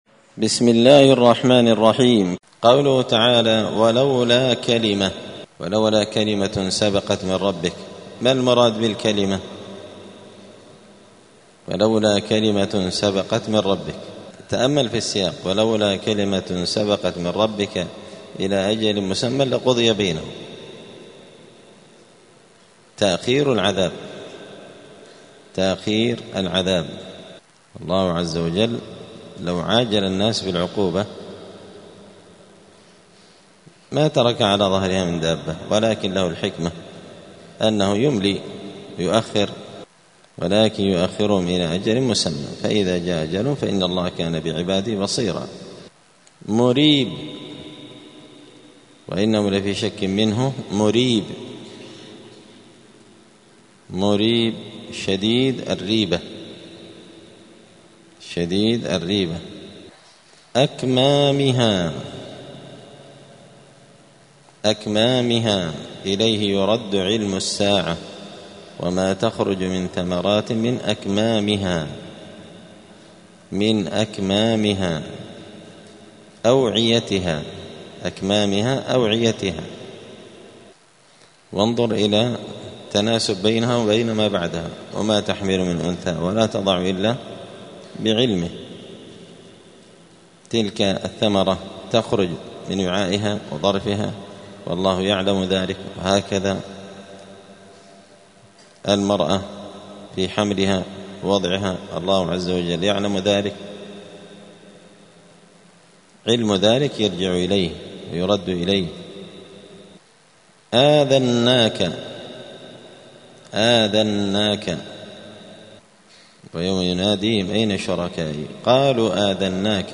الأربعاء 3 جمادى الآخرة 1446 هــــ | الدروس، دروس القران وعلومة، زبدة الأقوال في غريب كلام المتعال | شارك بتعليقك | 34 المشاهدات
دار الحديث السلفية بمسجد الفرقان قشن المهرة اليمن